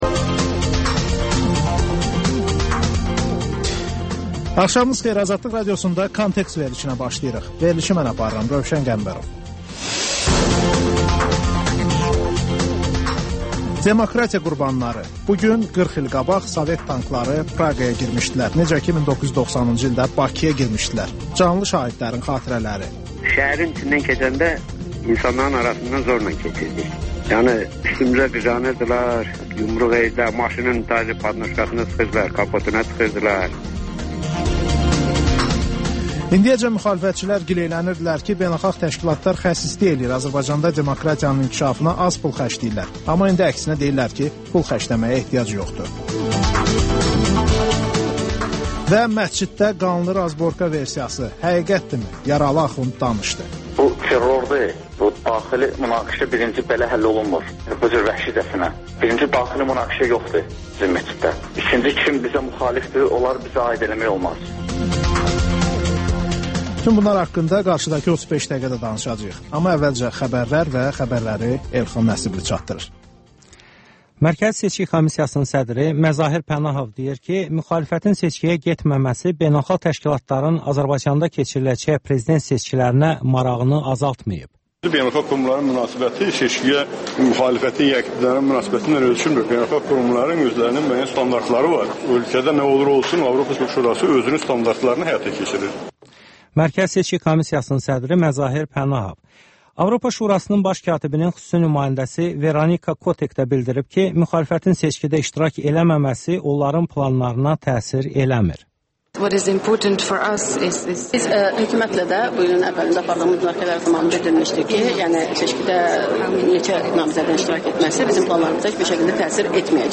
Xəbərlər, müsahibələr, hadisələrin müzakirəsi, təhlillər, sonda TANINMIŞLAR: Ölkənin tanınmış simaları ilə söhbət